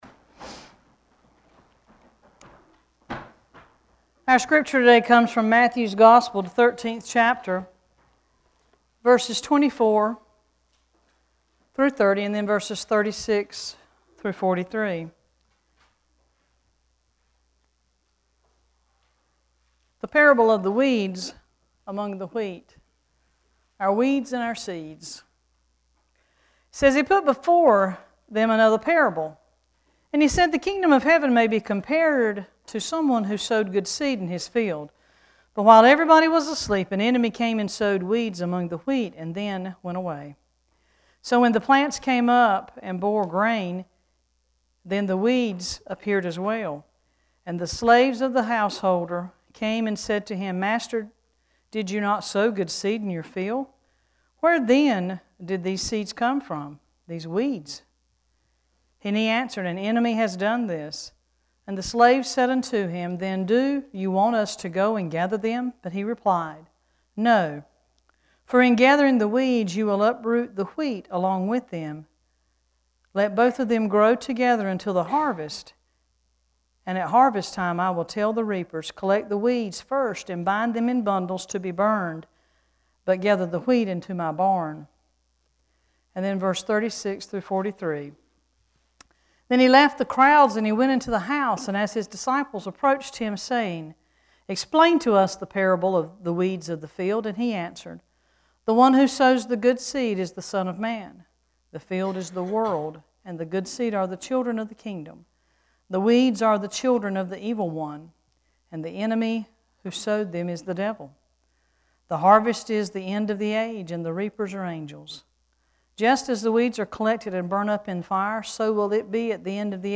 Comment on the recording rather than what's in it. Worship Service 2-16-14: Weeds and Seeds